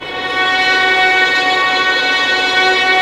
Index of /90_sSampleCDs/Roland - String Master Series/STR_Vlns Bow FX/STR_Vls Pont wh%